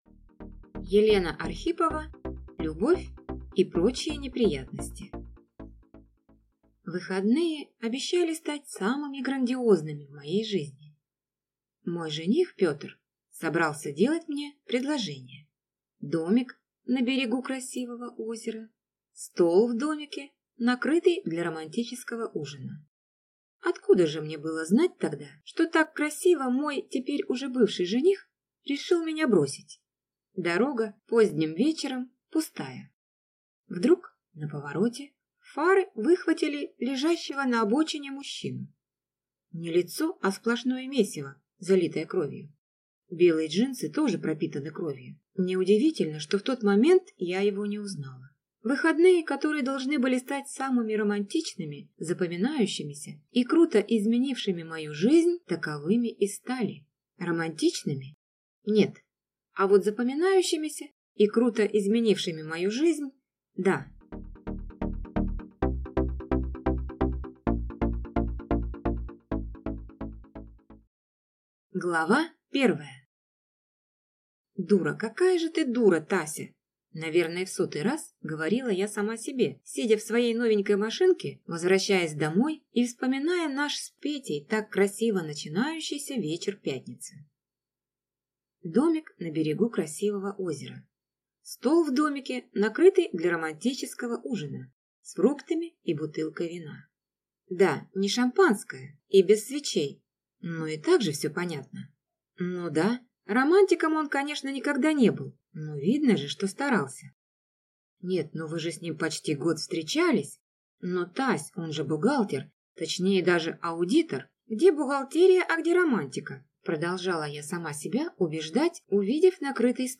Аудиокнига Любовь и прочие неприятности | Библиотека аудиокниг